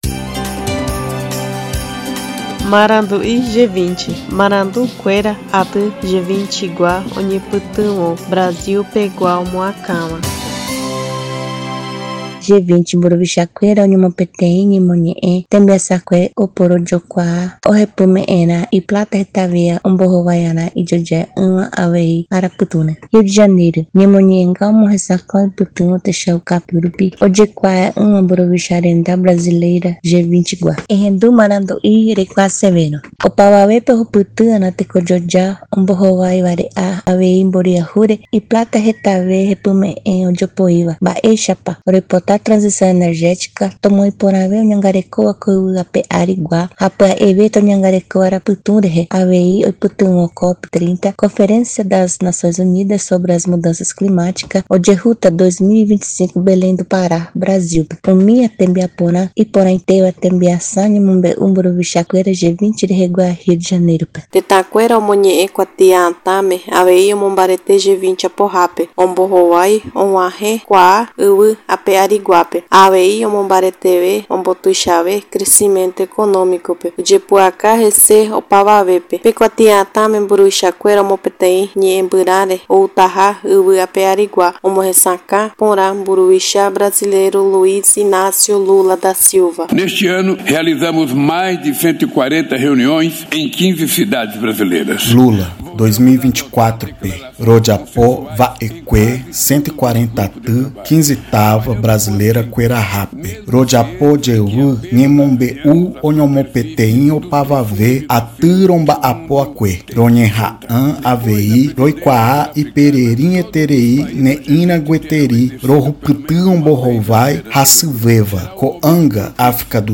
Com base em conhecimentos ancestrais, a bioeconomia é uma oportunidade de rendimentos na casa dos trilhões de dólares. Ouça a reportagem e saiba mais.